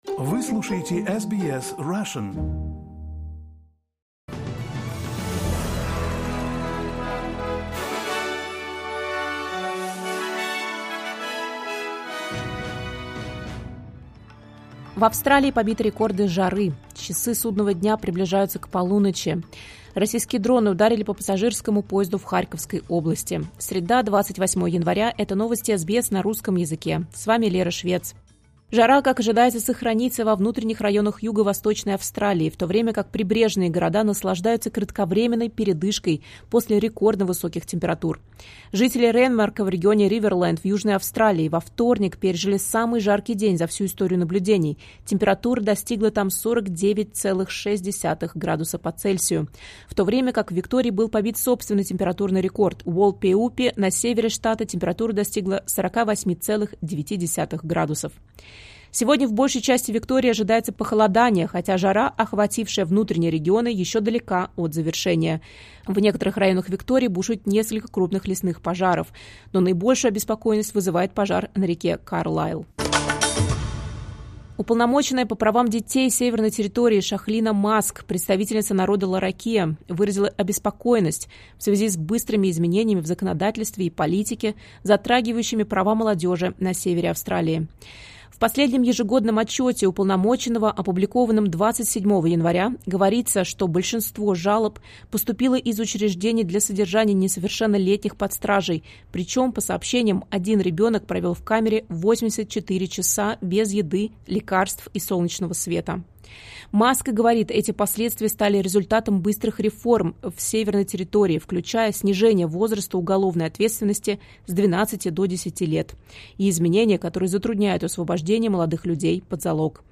Новости SBS на русском языке — 28.01.2026